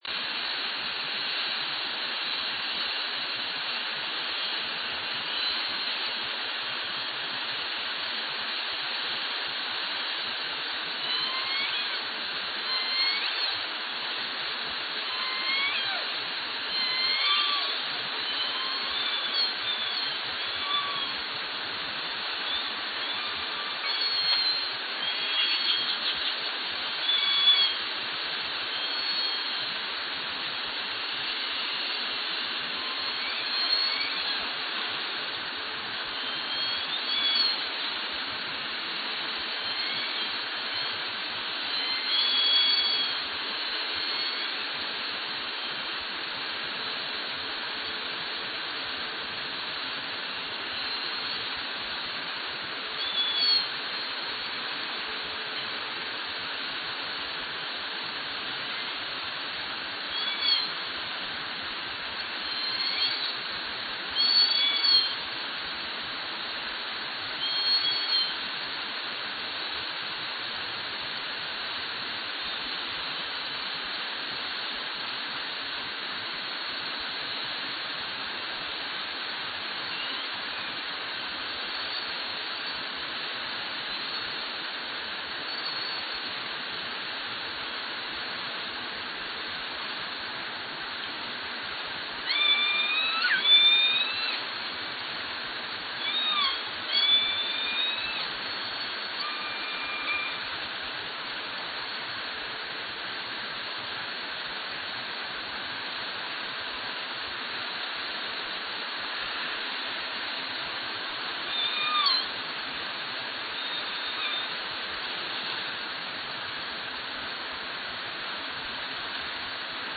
They were located early on by the Beam Reach boat off Hannah Heights and then were heard first on the Lime Kiln hydrophones, seen on the Center for Whale Research OrcaCam, and then heard on the Orcasound hydrophone. A few S17 calls were heard at 15:45 back down at Lime Kiln, after most animals had faded off to the north, and an S7 was just heard there.
Lime Kiln excerpt: